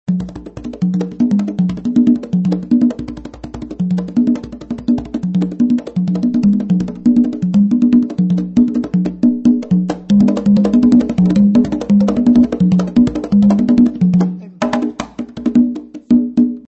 LP Giovanni Palladium Conga Sound Sample